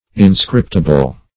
Inscriptible \In*scrip"ti*ble\, a. Capable of being inscribed; inscribable.